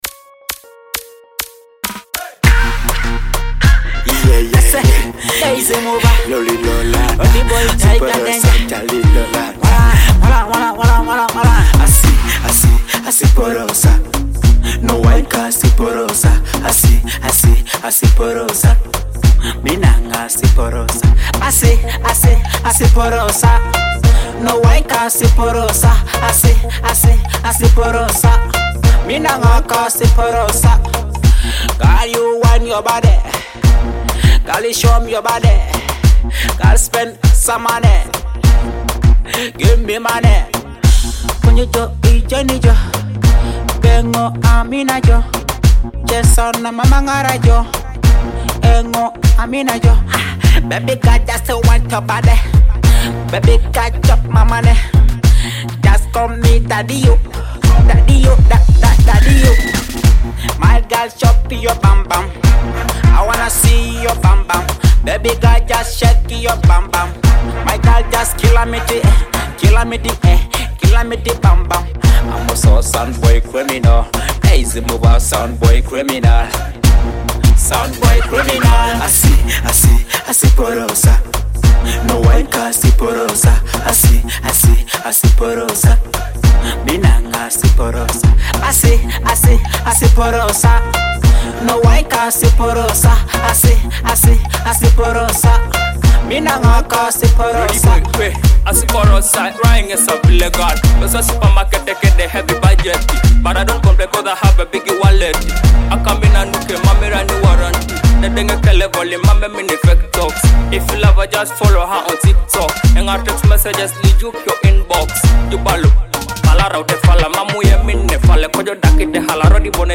a high-energy dancehall hit
an electrifying dancehall hit